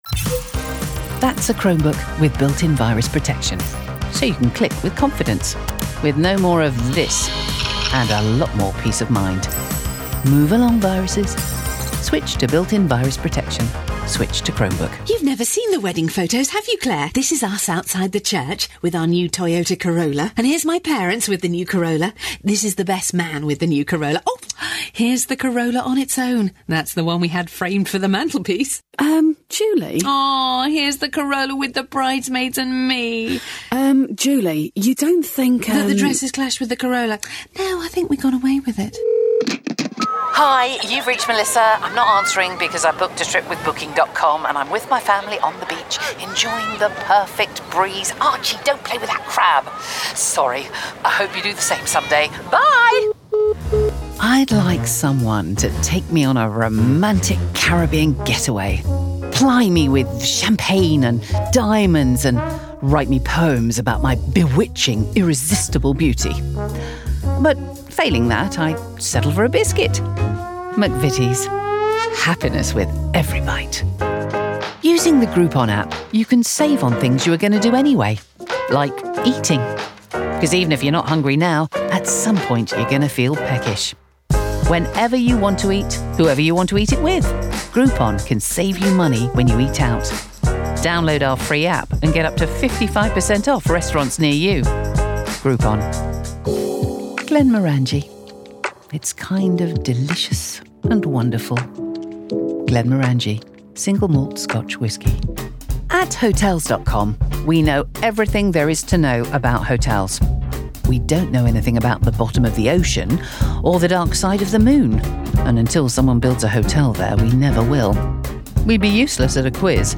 Commercial Showreel
Straight